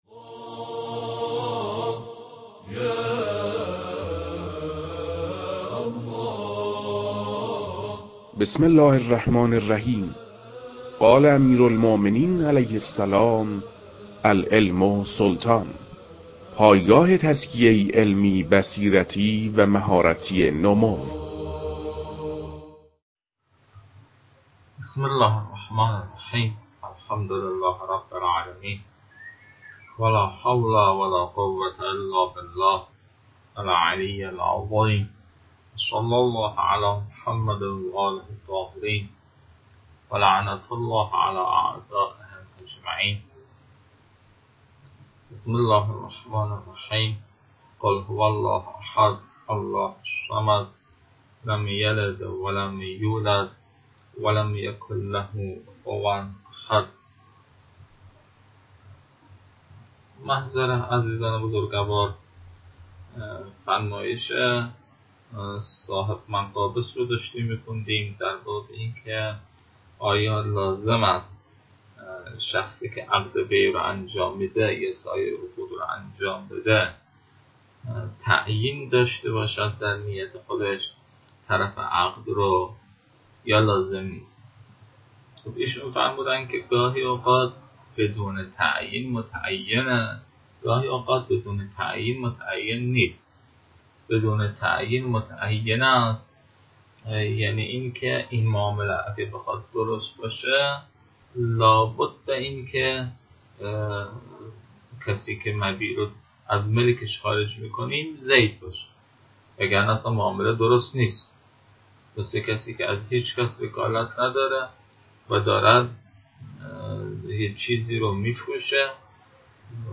در این بخش، فایل های مربوط به تدریس مباحث تنبیهات معاطات از كتاب المكاسب متعلق به شیخ اعظم انصاری رحمه الله